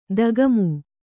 ♪ dagamu